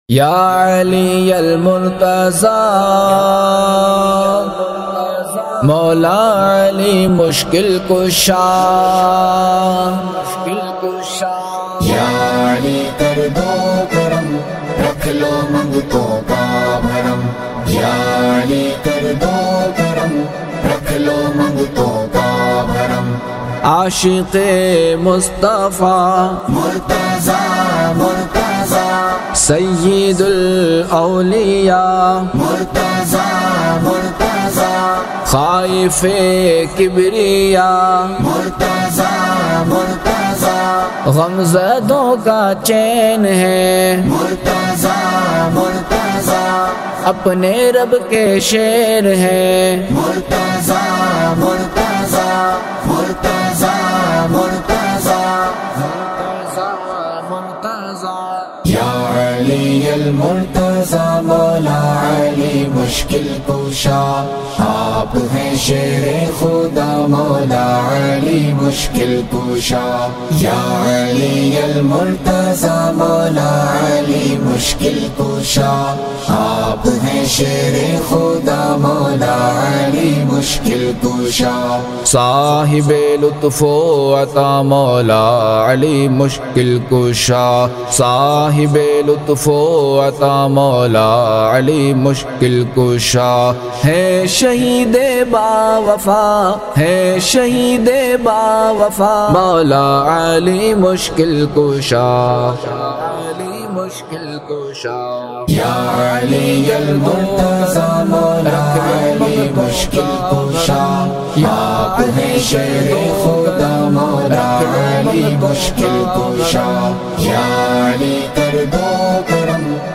New Manqabat